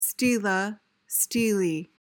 PRONUNCIATION: (STEE-luh) MEANING: noun: An upright stone or pillar inscribed or sculpted, often serving as a monument.